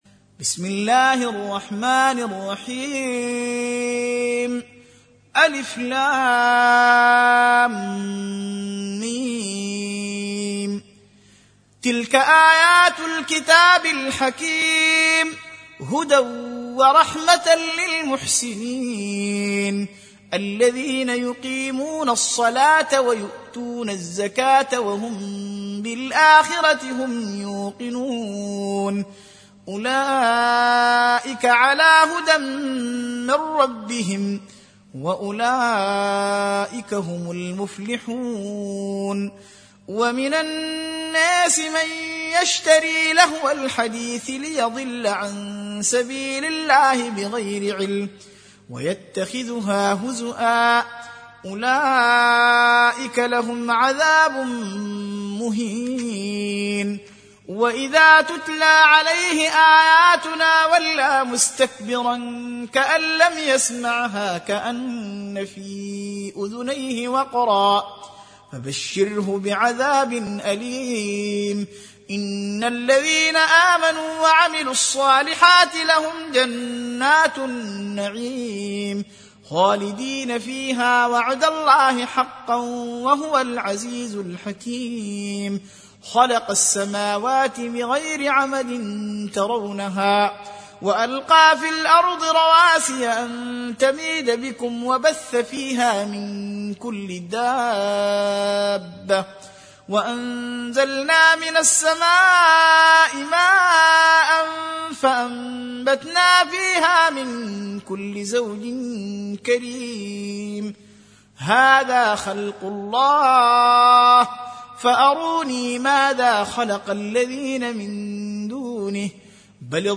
31. Surah Luqm�n سورة لقمان Audio Quran Tarteel Recitation
Surah Repeating تكرار السورة Download Surah حمّل السورة Reciting Murattalah Audio for 31. Surah Luqm�n سورة لقمان N.B *Surah Includes Al-Basmalah Reciters Sequents تتابع التلاوات Reciters Repeats تكرار التلاوات